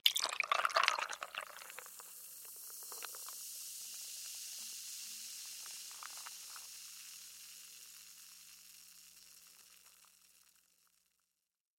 Звуки коктейля
Газировку добавили в коктейль (например, швепс)